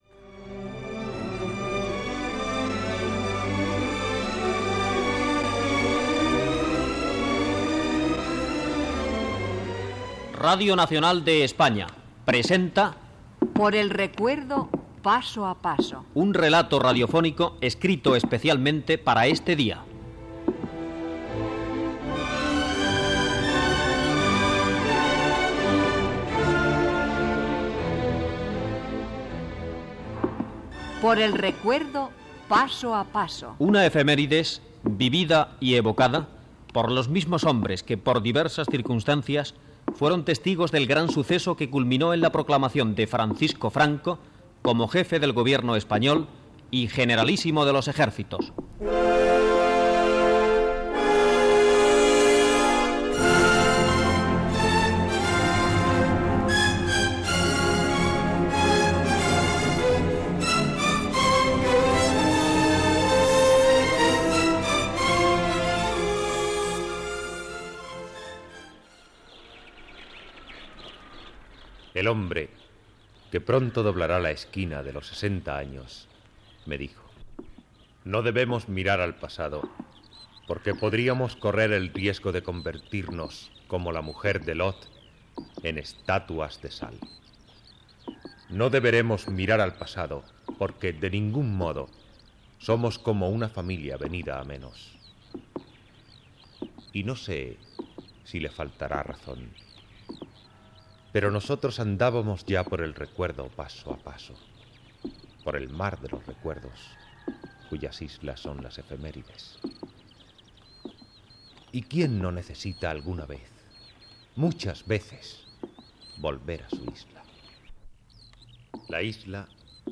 Careta del programa.
Descricpció de l'ambient que es vivia a Salamanca aquell dia, cançó militar